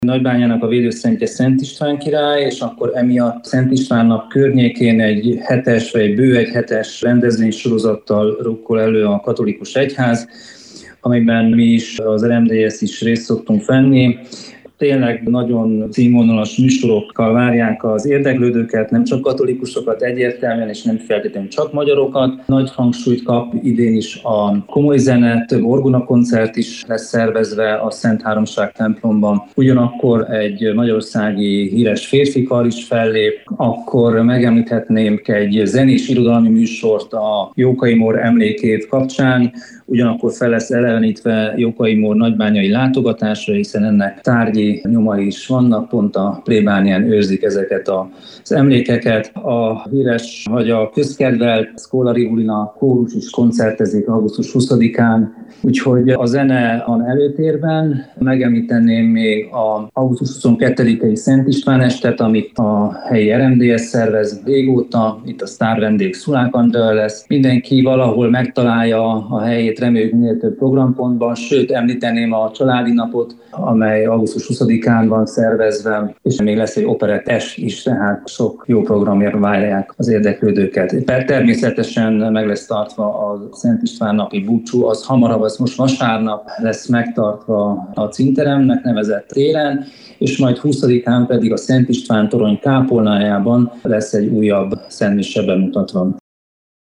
A részletekről Pintér Zsolt megyei tanácsos mesélt rádiónknak.